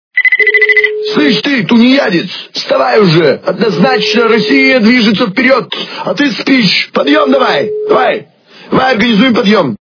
» Звуки » Люди фразы » Владимир Жириновский - Вставай, тунеядец
При прослушивании Владимир Жириновский - Вставай, тунеядец качество понижено и присутствуют гудки.